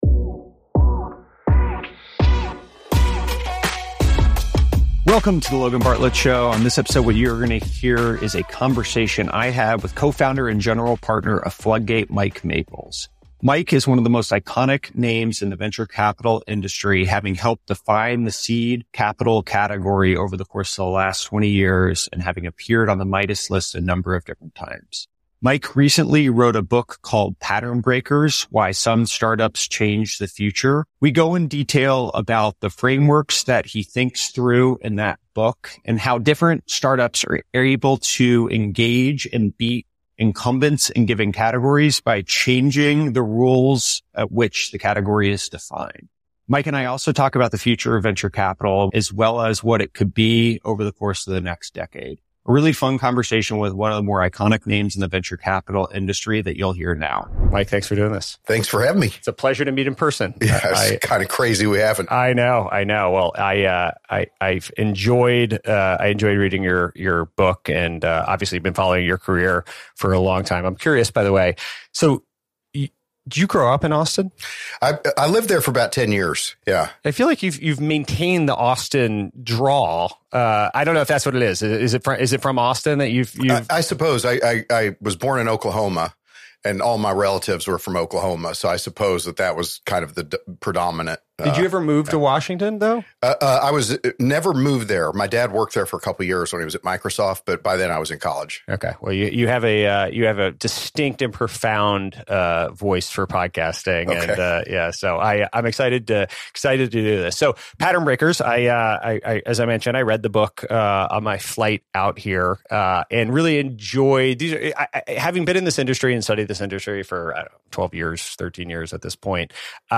He explores how top startups redefine categories and discusses the significance of 'pattern-breaking' ideas. The conversation dives into pivotal moments in startup history, including the rise of Twitch and Tesla.